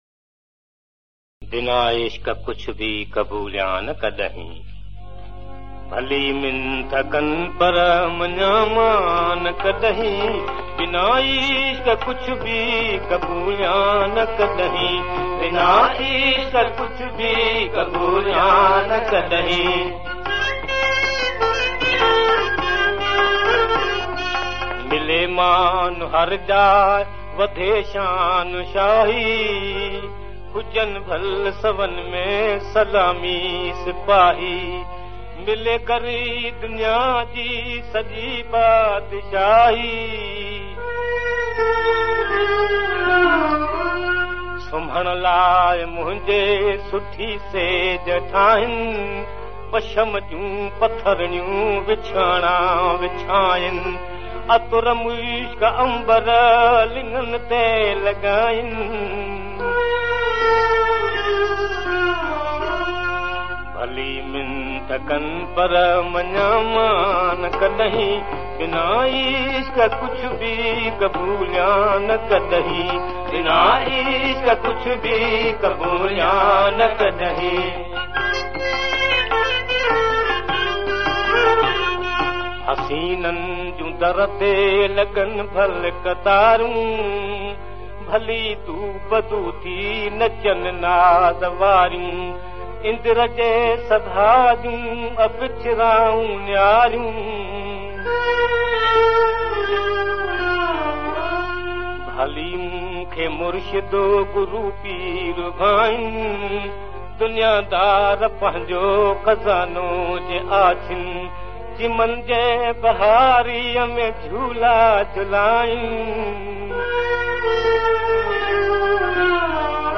Classic Sindhi Songs